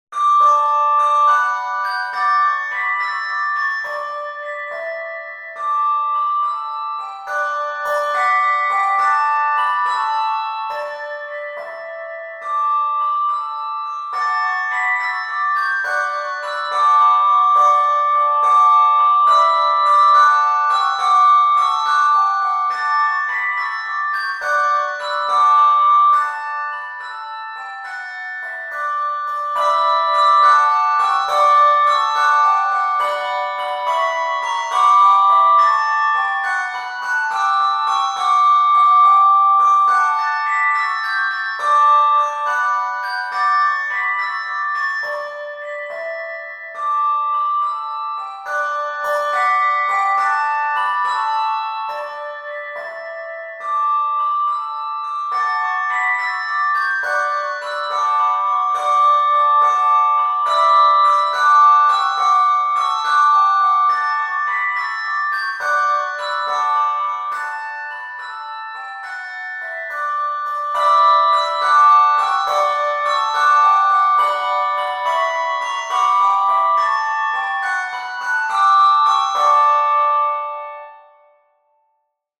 handbells
Advent and Christmas songs arranged for 3 – 6 musicians